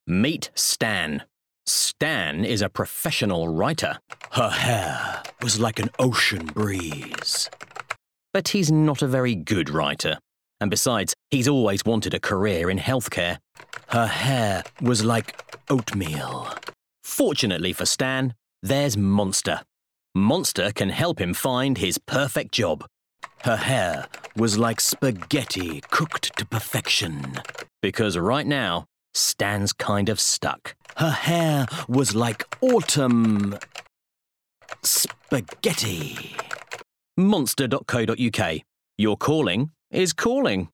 40's Neutral/RP,
Friendly/Confident/Natural
Commercial Showreel